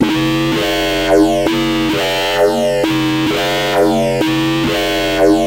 巴斯12
描述：贝斯12
标签： 175 bpm Drum And Bass Loops Bass Loops 1.24 MB wav Key : Unknown
声道立体声